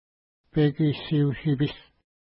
Pronunciation: peki:si:u-ʃi:pi:s
Pronunciation